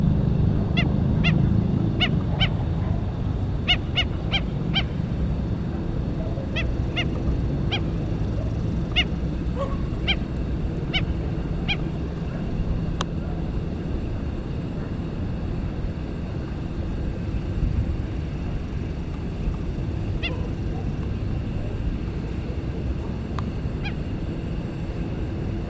Black-necked Stilt (Himantopus mexicanus)
Canto
Life Stage: Adult
Detailed location: Laguna San Martin
Condition: Wild
Certainty: Observed, Recorded vocal
Tero-real--canto.mp3